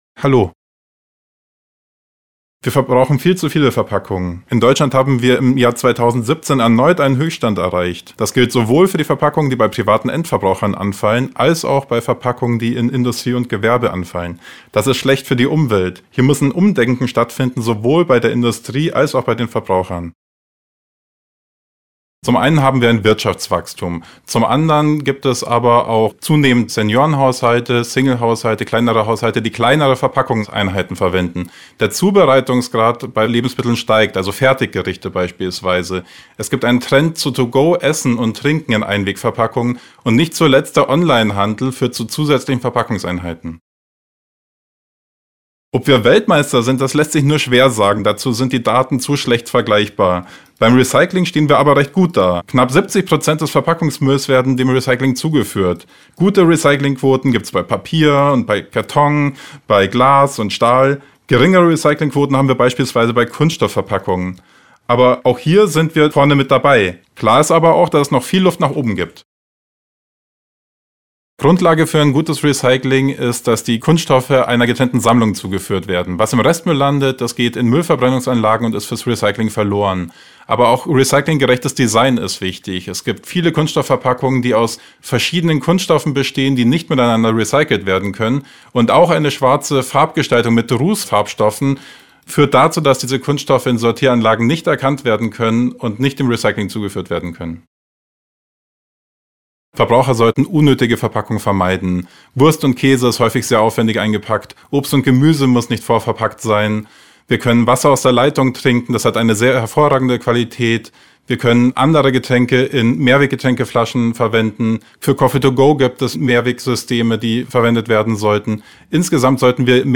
Interview: 2:05 Minuten